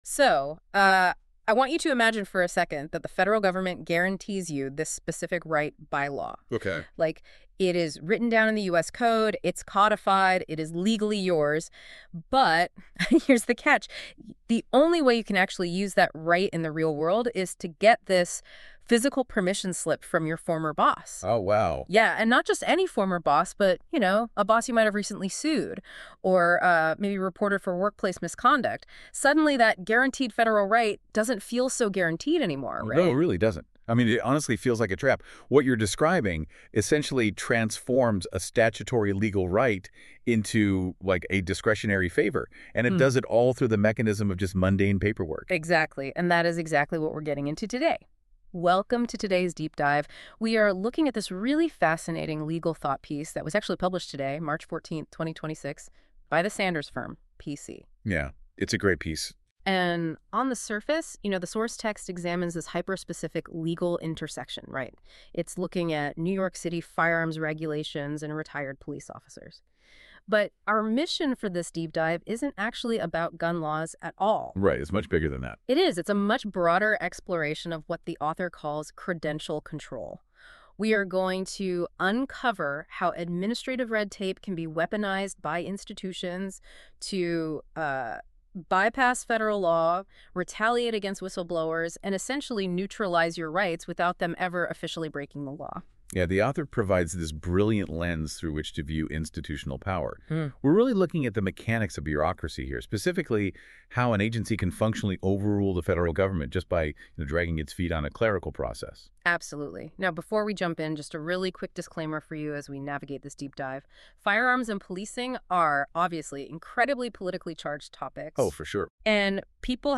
Second, a Deep-Dive Podcast that expands on the analysis in conversational form. The podcast explores the historical context, legal doctrine, and real-world consequences in greater depth, including areas that benefit from narrative explanation rather than footnotes.